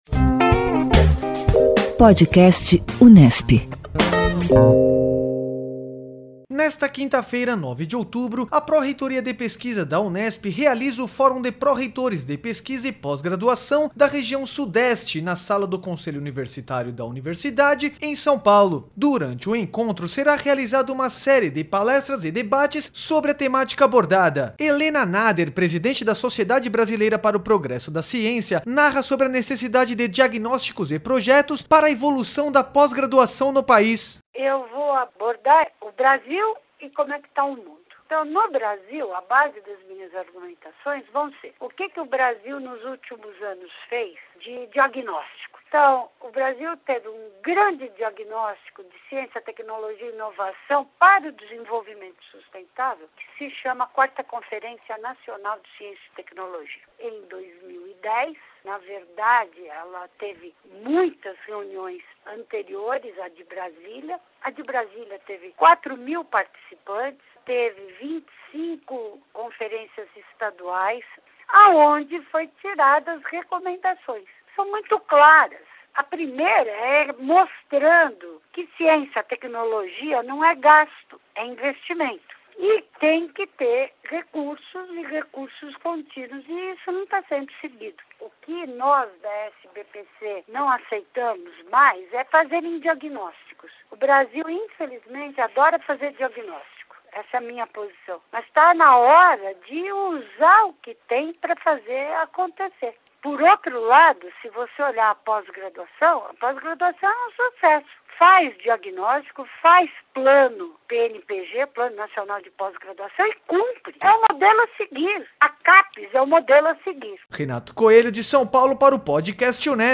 Helena Nader, presidente da Sociedade Brasileira para o Progresso da Ciência, narra sobre necessidade de diagnósticos e projetos para a evolução da pós-graduação no país.
O Podcast Unesp / Assessoria de Comunicação e Imprensa da Reitoria da Unesp traz entrevistas com professores, pesquisadores e alunos sobre pautas cotidianas da mídia brasileira, internacional e informações geradas na Universidade.